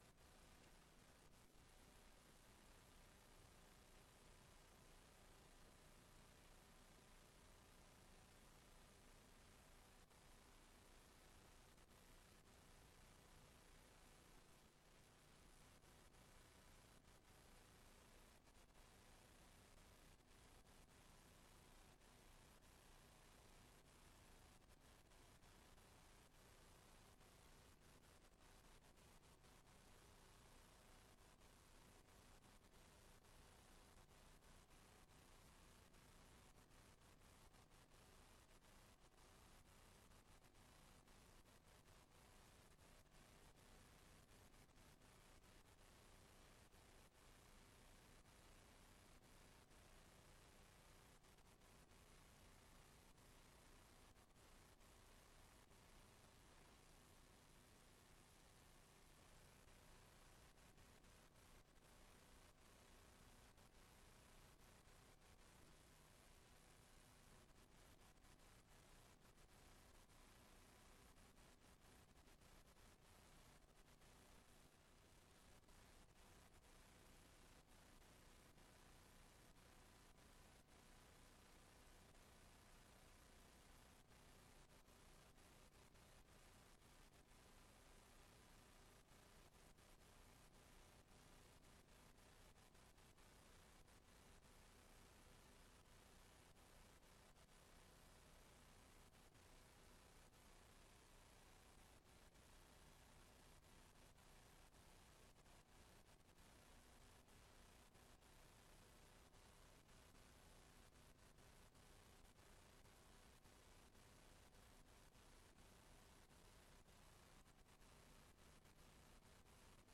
Raadsbijeenkomst 24 juni 2025 19:00:00, Gemeente Tynaarlo
Locatie: Raadszaal